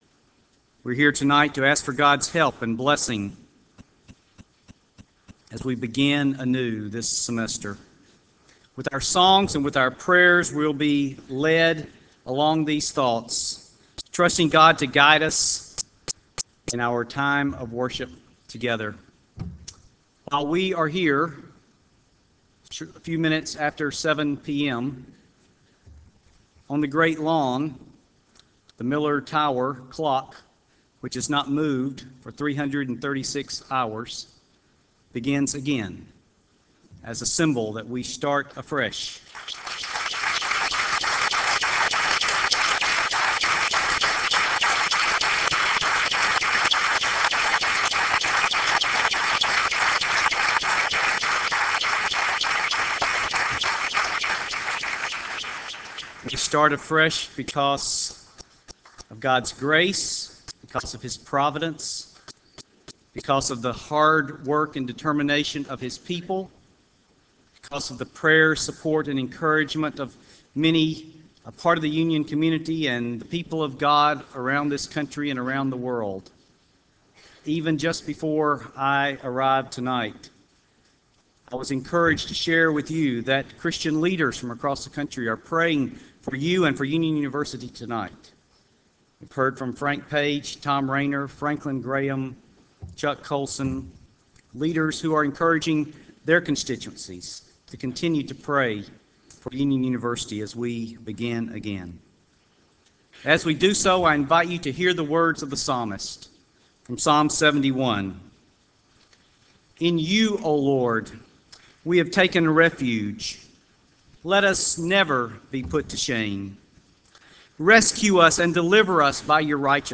Campus-wide Worship Service